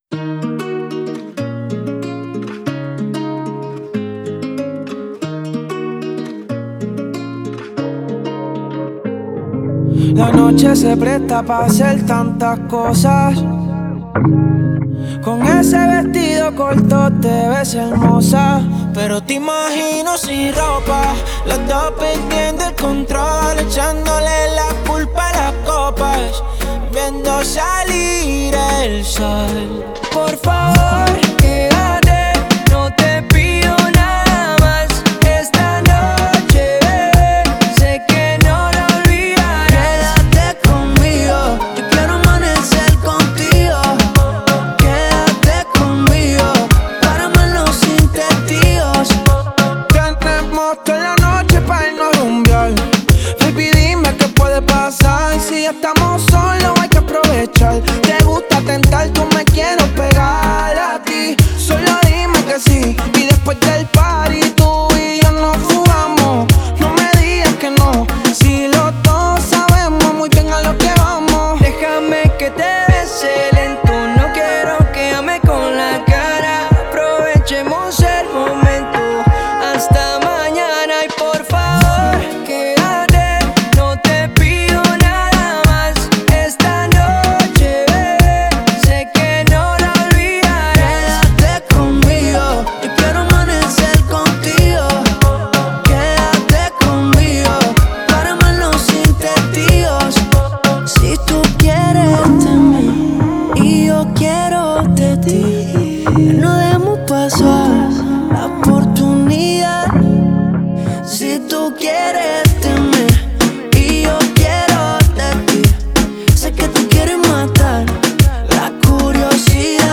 зажигательная песня в жанре латинского попа